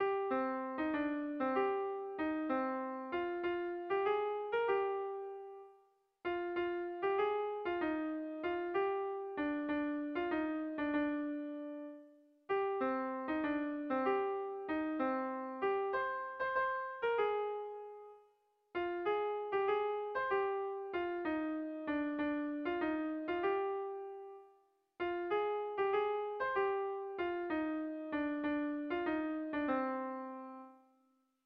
Kontakizunezkoa
Zortziko txikia (hg) / Lau puntuko txikia (ip)
A1BA2D1D2